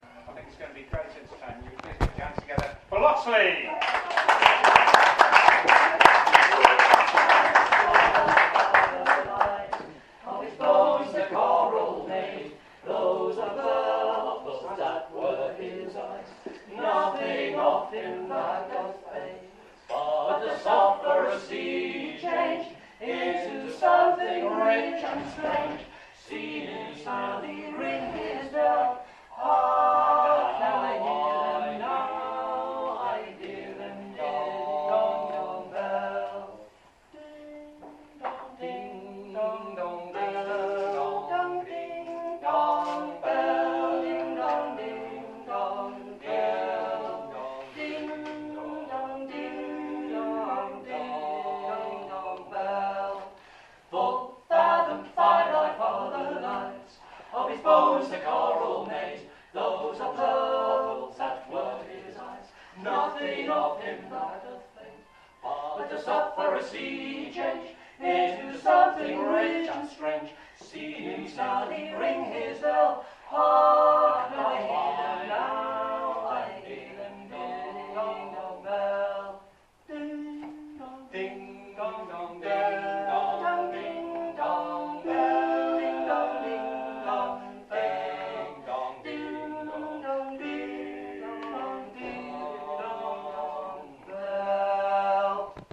Loxley Opening Number at Banbury Folk Festival 2011